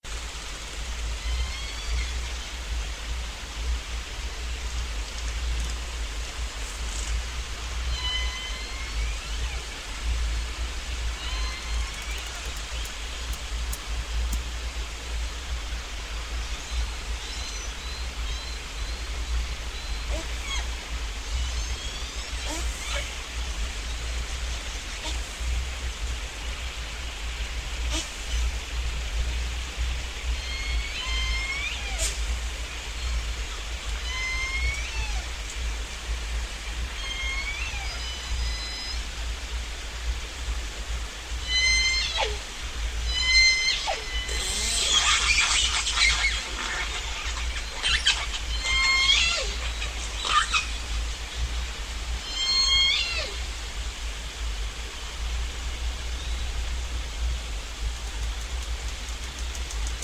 Eventually we found ourselves surrounded by these whales.Â We stayed there for hours and got great recordings from the vocal J-pod members that were all around.
Â Here are a few short clips. many S1 calls J-pod 5/10/11 What a great feeling.Â We all could walk back on to land with our whale fix.
count-the-S1s-amplified.mp3